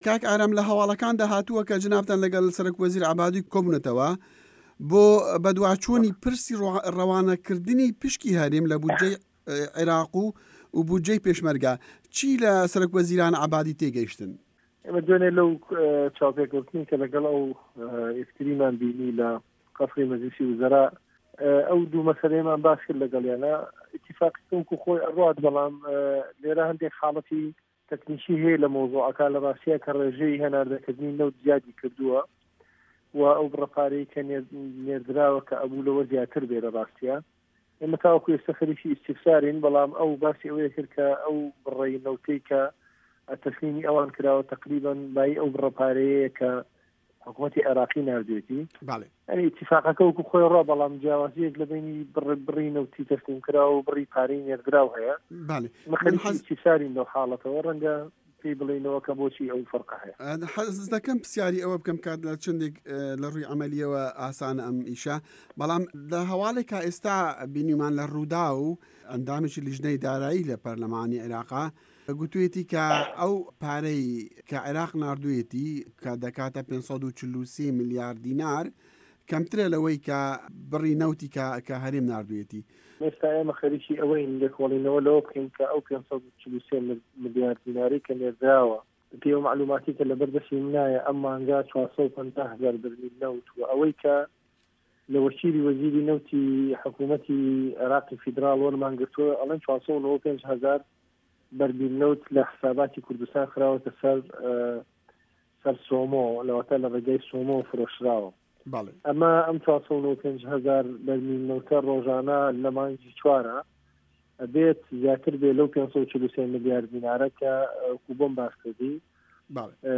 وتووێژی ئارام شێخ محه‌مه‌د